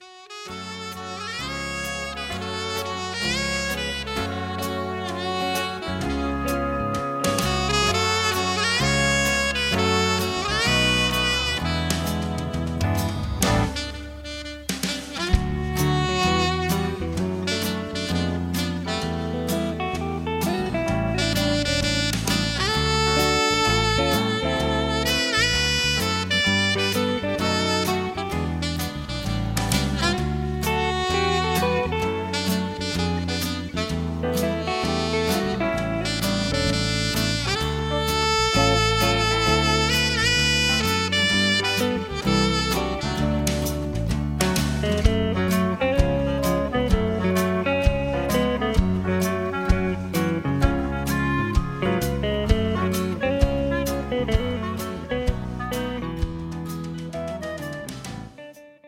Instrumental
Singing Calls